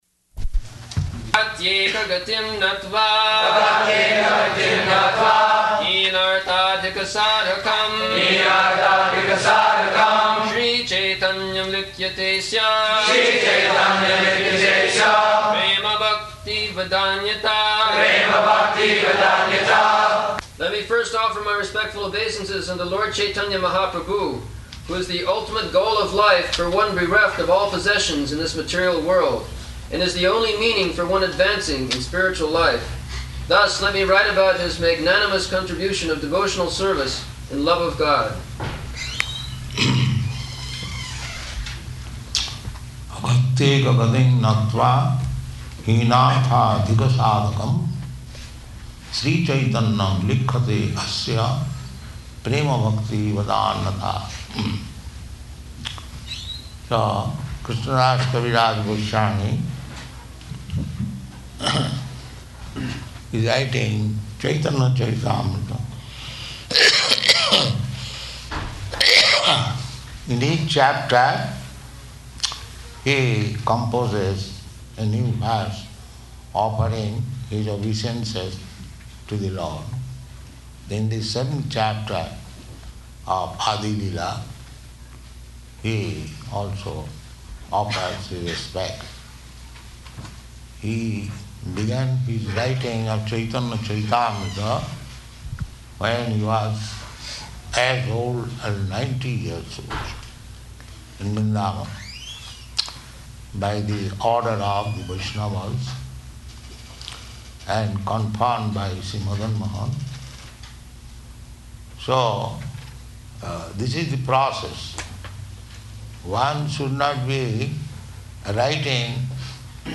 Location: Atlanta
[leads chanting of verse, etc.]